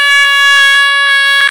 Balloon Sample P 5